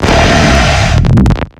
Cri de Darkrai dans Pokémon X et Y.